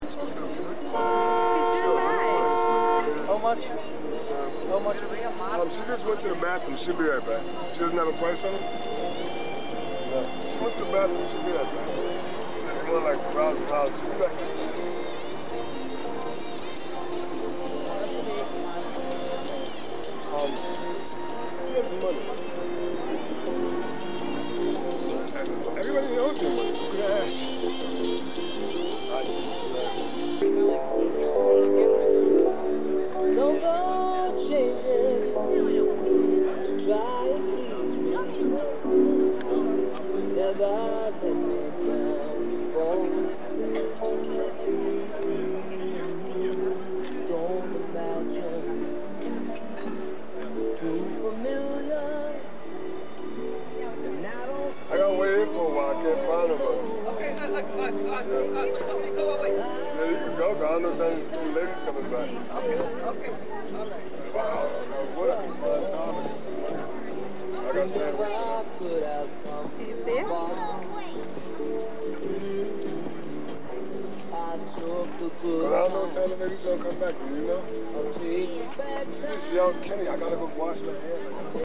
New York City's Soho flea market, August 1993.